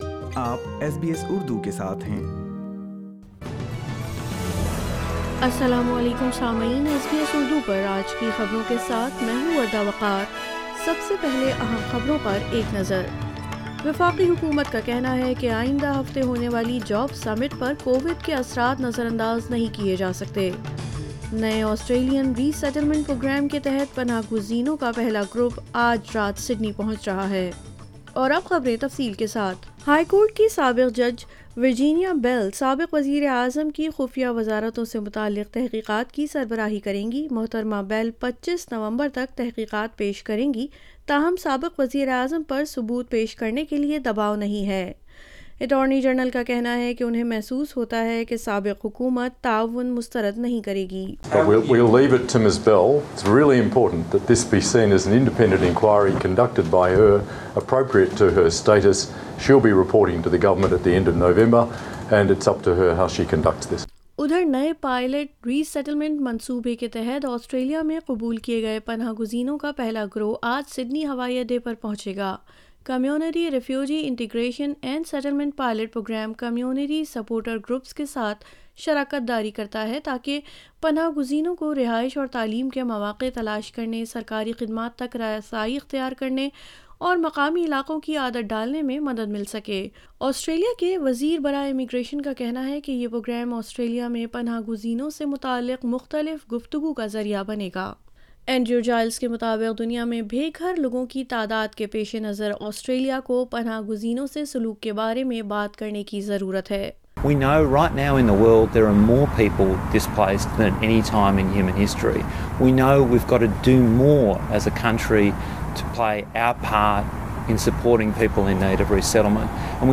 Urdu News Friday 26 August 2022